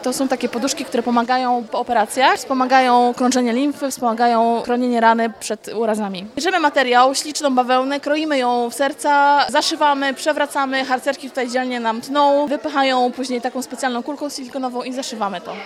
Dziś rano w bibliotece Norwida zebrało się ponad 30 osób, aby wspomóc osoby po mastektomii i operacjach oszczędzających.